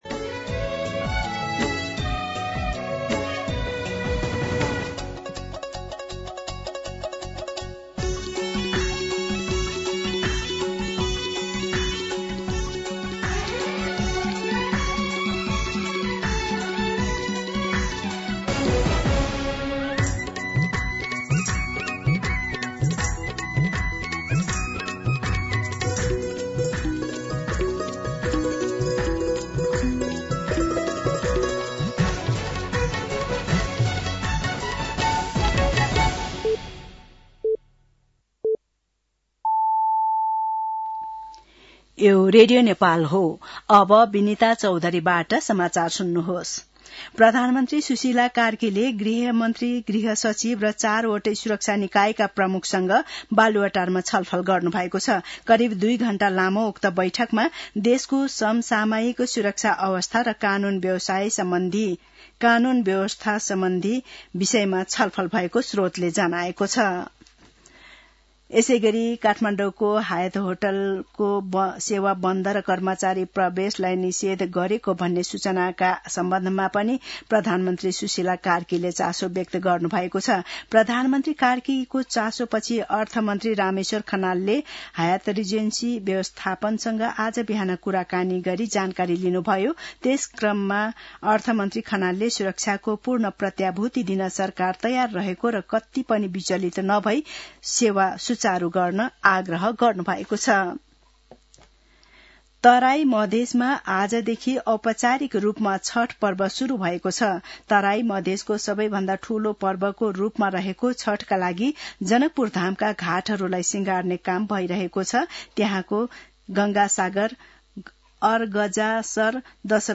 दिउँसो १ बजेको नेपाली समाचार : ८ कार्तिक , २०८२
1-pm-Nepali-News-12.mp3